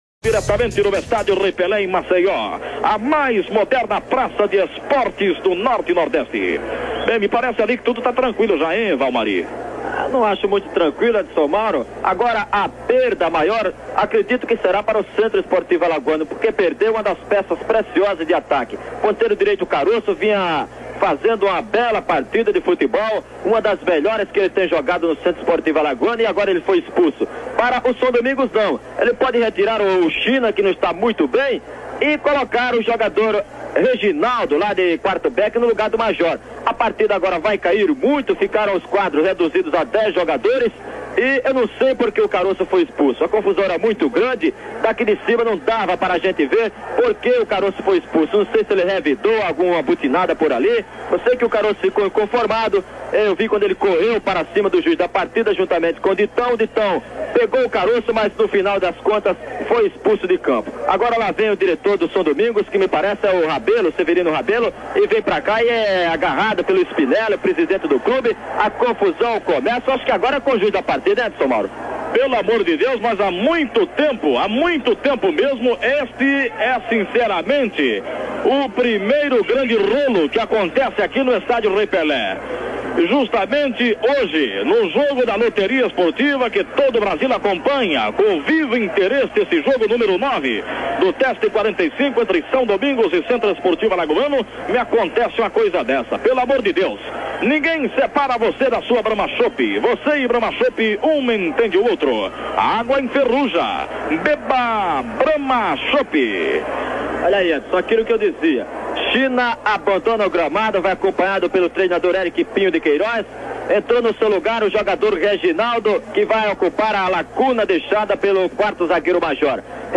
No dia 12 de junho de 1971, a Rádio Globo transmitiu o jogo São Domingos x CSA, realizado no Trapichão com início às 20 horas.
(Ouça aqui Edson Mauro narrando os cinco minutos finais do 1º tempo dessa partida).